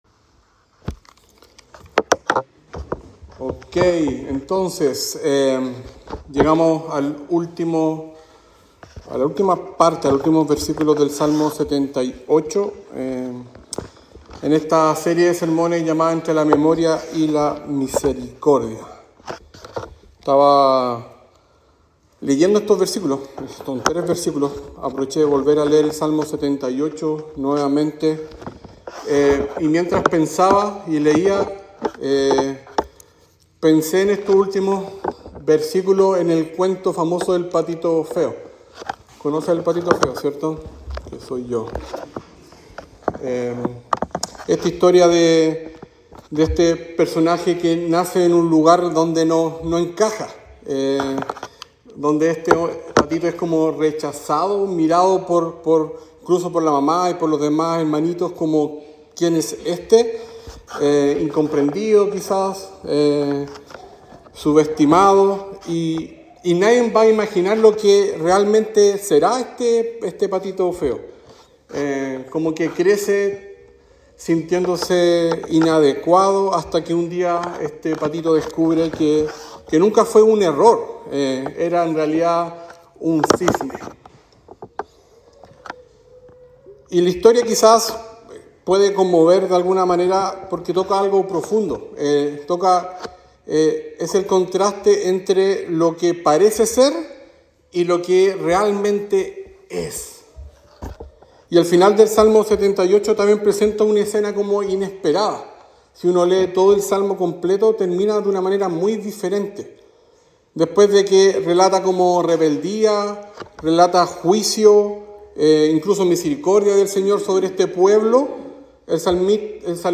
Sermón sobre Salmo 78